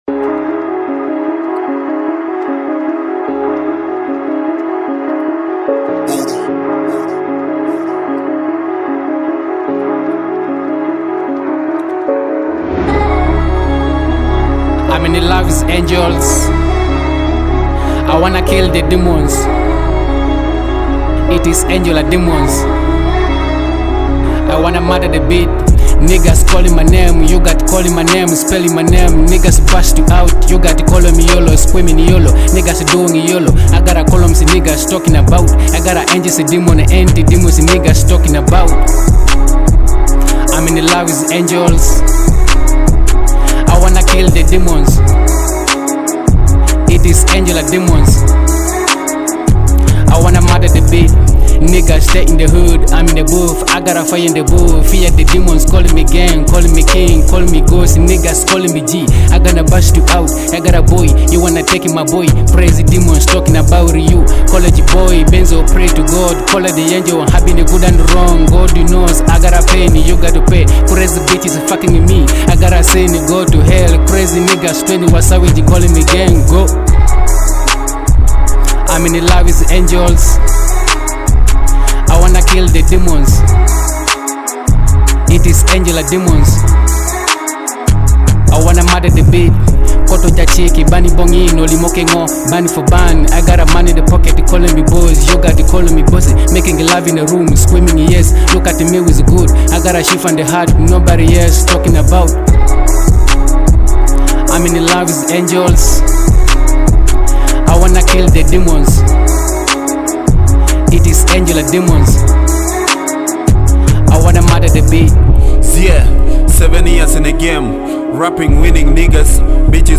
Blending raw lyricism with dynamic beats